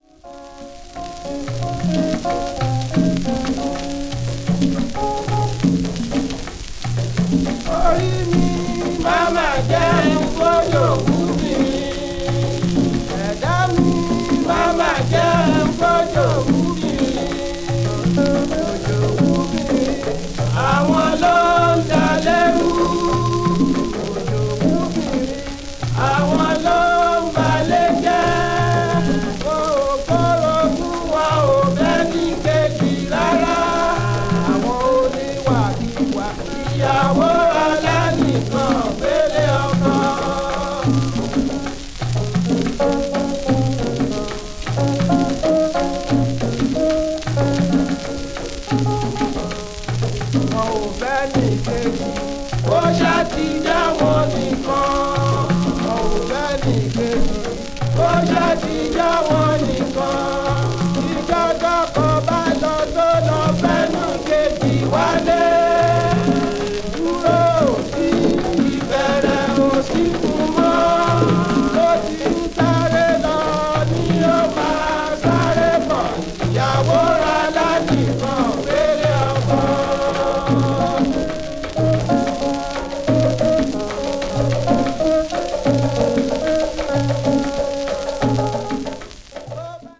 バックグラウンド・ノイズはSP盤に起因するものです。
africa   classical   traditional   world music